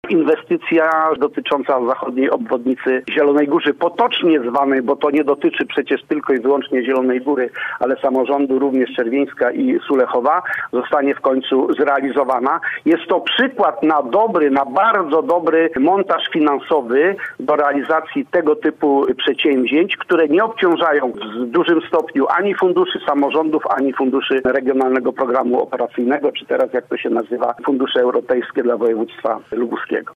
Radny wojewódzki z Samorządowego Lubuskiego był gościem Rozmowy po 9: